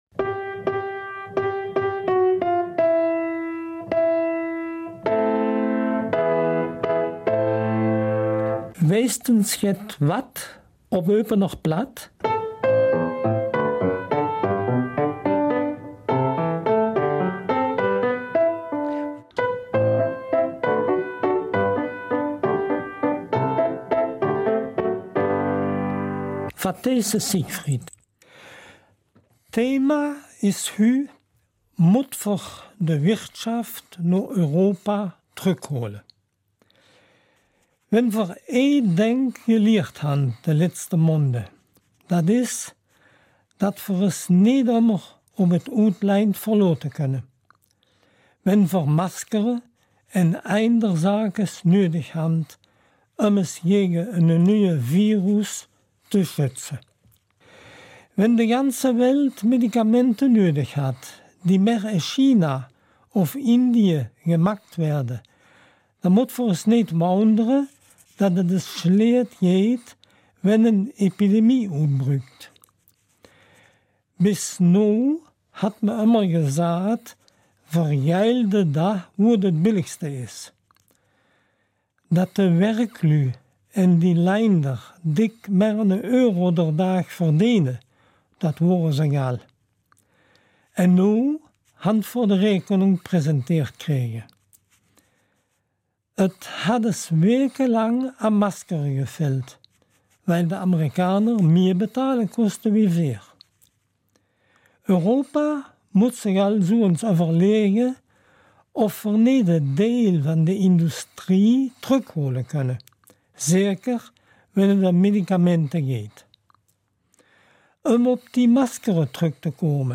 Außerdem geht es u. a. um die Herkunft plattdeutscher Wörter und einem Auszug aus ''der kleine Prinz'' auf Eupener Platt.